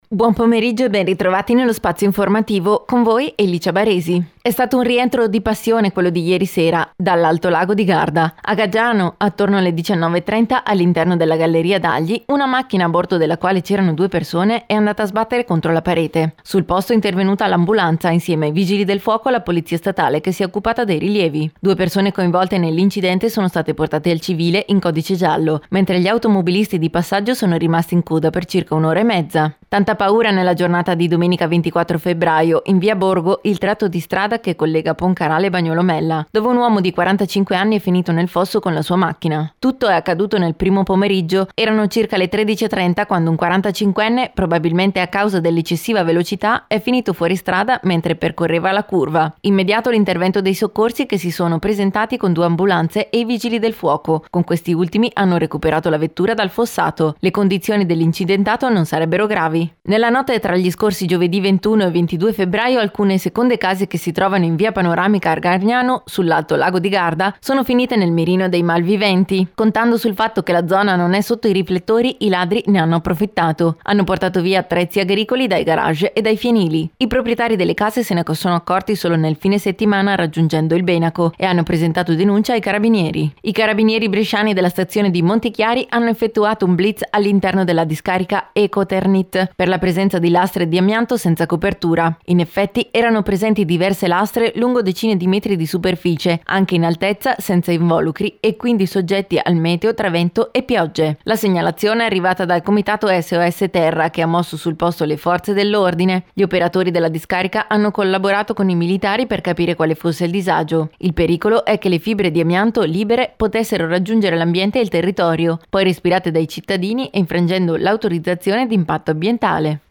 RadioGiornale del 25 Febbraio 2019 – Pomeriggio